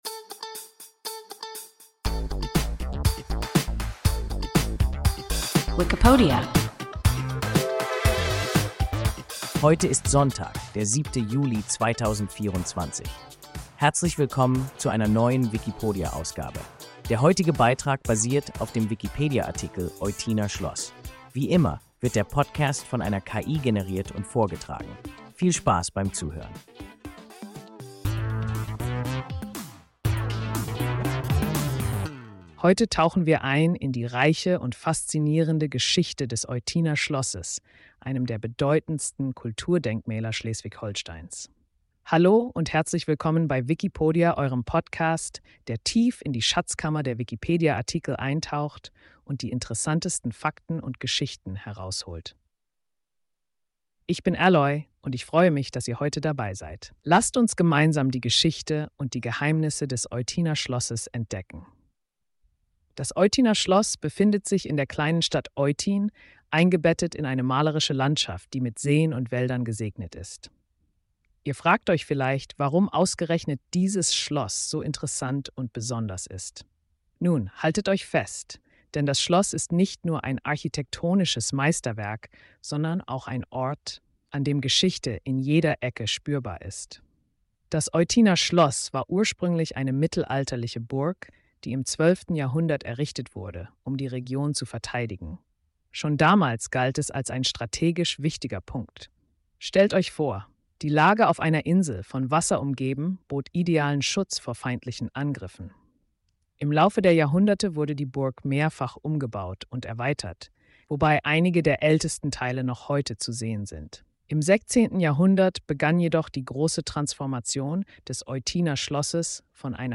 Eutiner Schloss – WIKIPODIA – ein KI Podcast